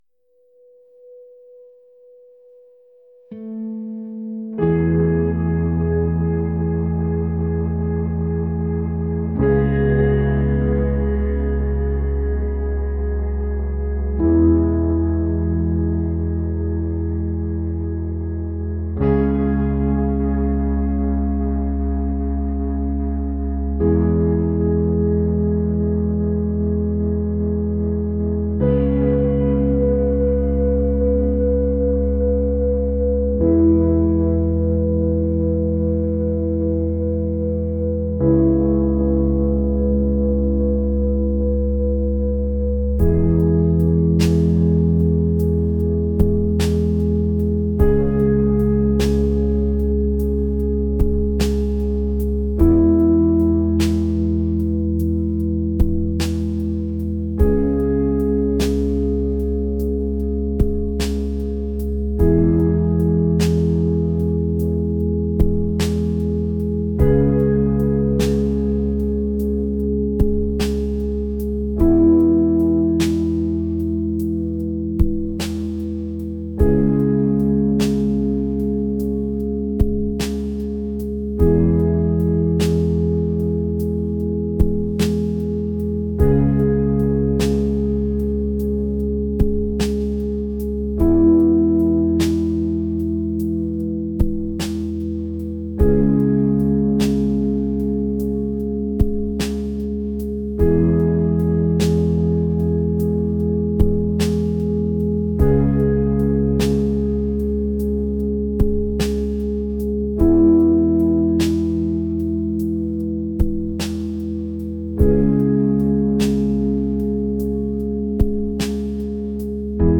pop | ethereal | atmospheric